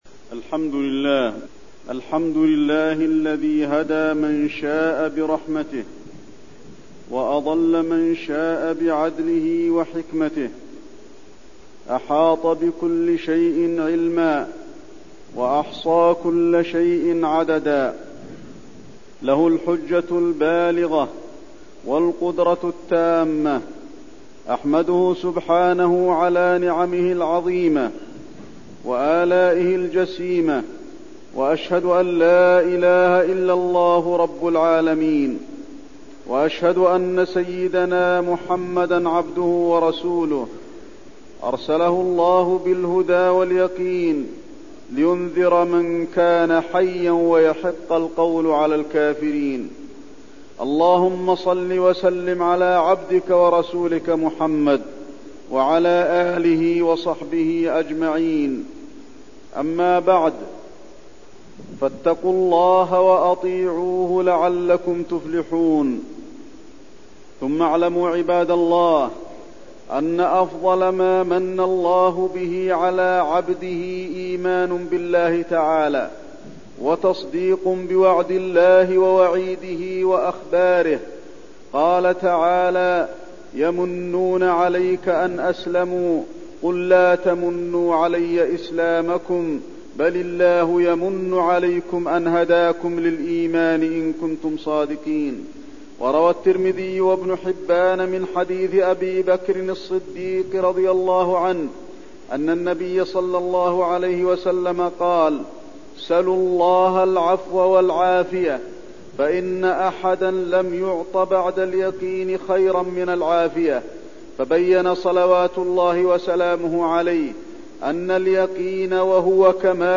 تاريخ النشر ٢٤ شوال ١٤٠٥ هـ المكان: المسجد النبوي الشيخ: فضيلة الشيخ د. علي بن عبدالرحمن الحذيفي فضيلة الشيخ د. علي بن عبدالرحمن الحذيفي العفو والعافية